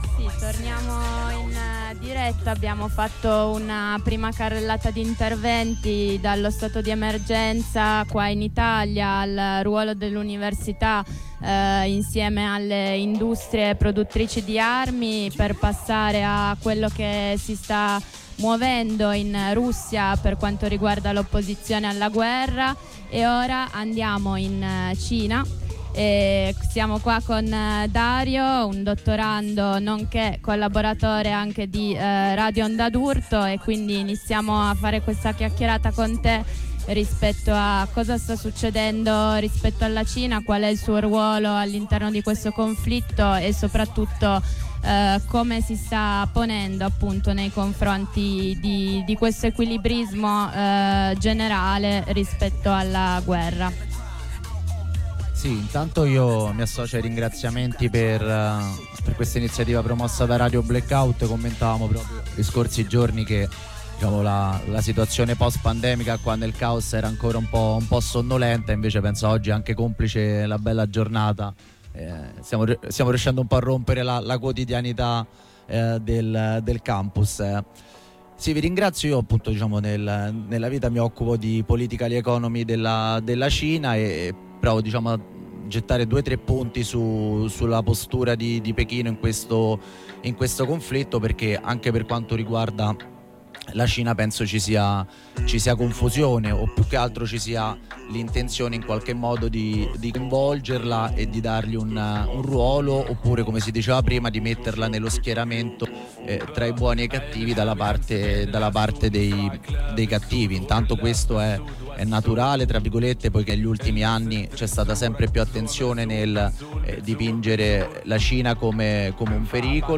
L’informazione del mattino di Radio Black Out esce dallo studio e si inerpica con lo studio mobile sulla collina del campus Einaudi per 3 ore di approfondimenti, voci e analisi sulla guerra in corso.